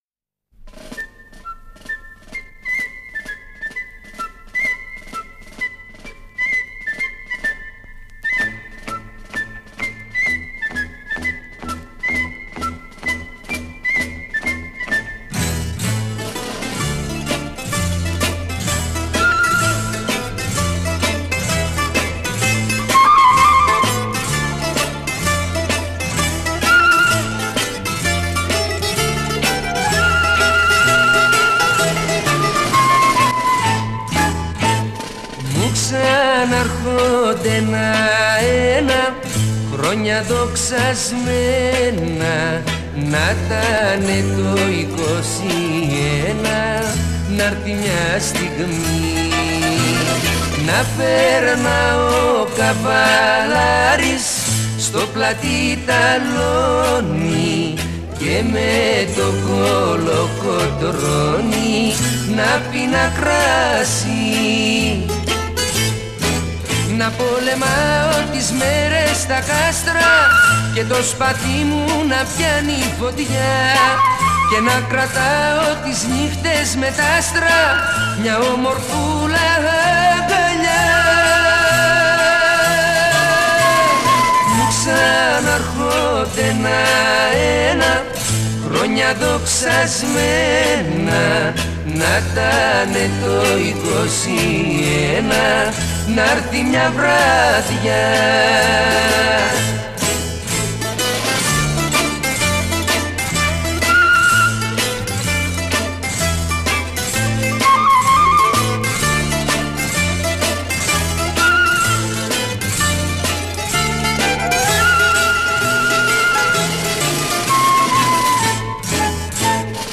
Audycja okolicznościowa z okazji rocznicy wyzwolenia Grecji z 400-letniej niewoli tureckiej